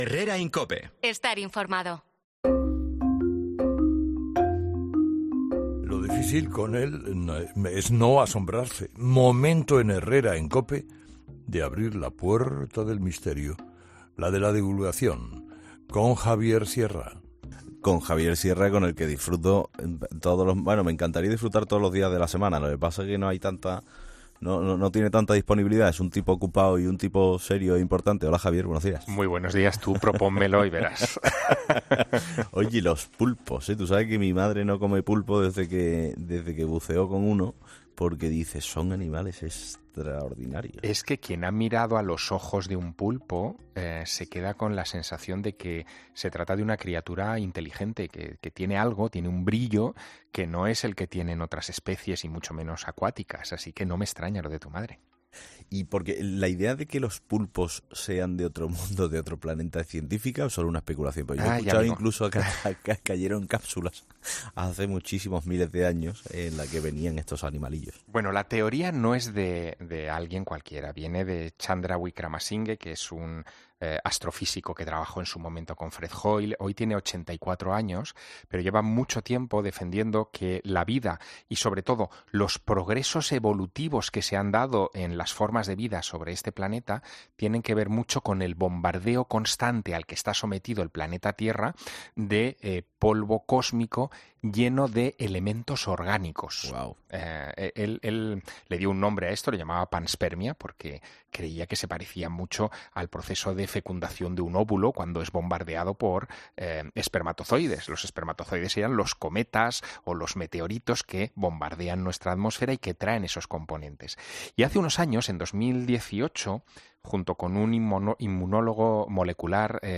El escritor explicaba en 'Herrera en COPE' uno de los estudios que más ha dado que hablar entre la comunidad científica